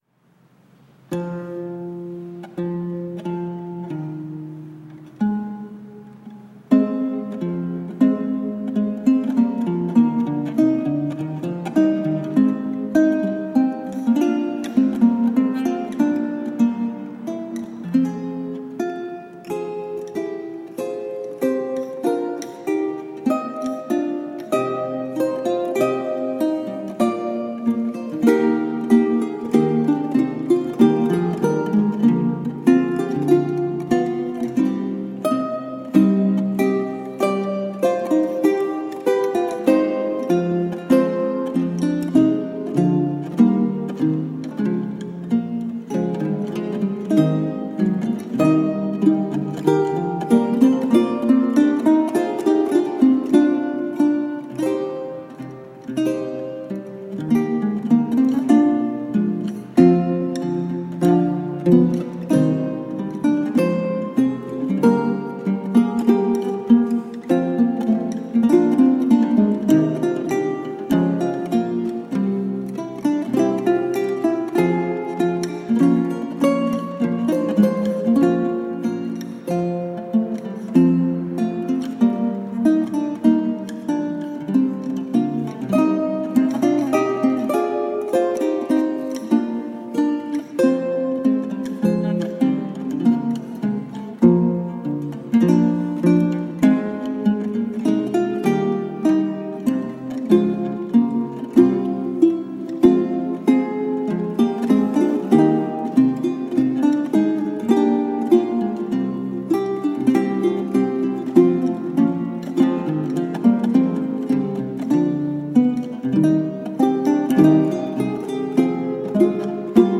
Spanish renaissance vihuela duets.